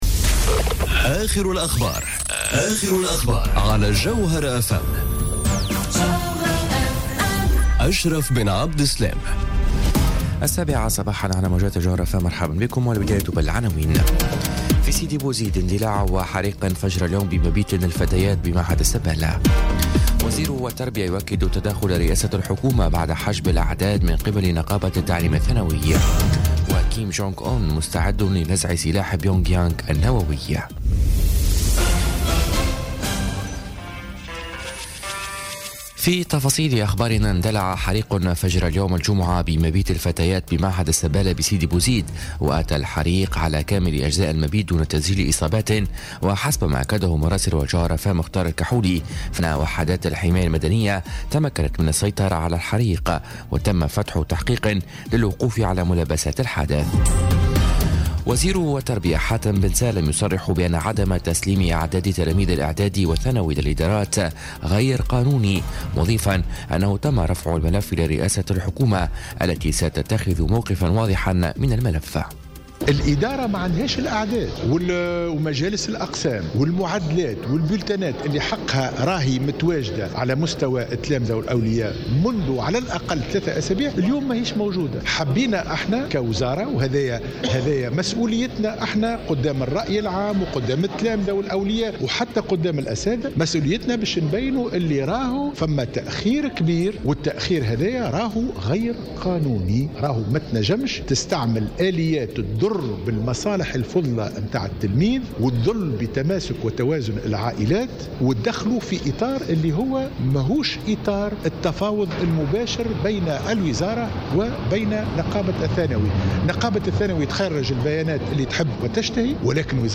نشرة أخبار السابعة صباحا ليوم الجمعة 9 مارس 2018